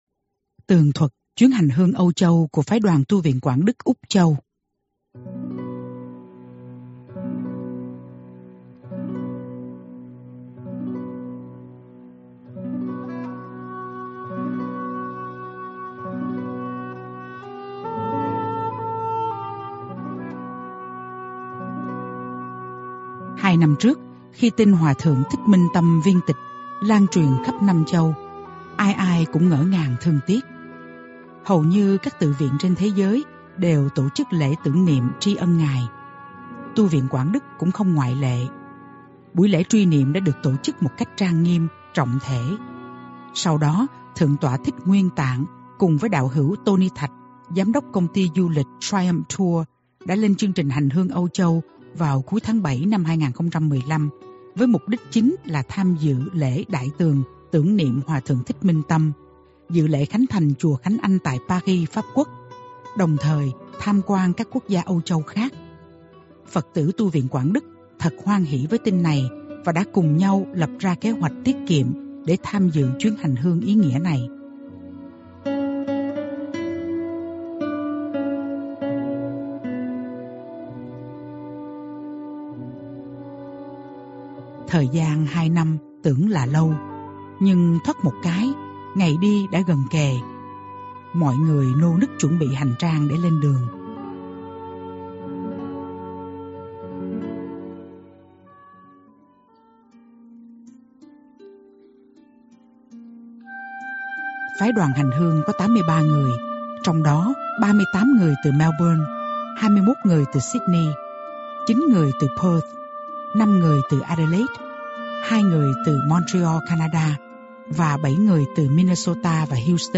Bài pháp thoại hôm nay là bài giảng thứ 271 của Sư Phụ bắt đầu từ mùa cách ly do bệnh dịch covid 19 (đầu tháng 5-2020). Sư Phụ nhắc, đời thứ 7 dòng Vô Ngôn Thông có 3 vị thiền sư, thiền sư Bảo Tánh, thiền sư Minh Tâm và thiền sư Quảng Trí.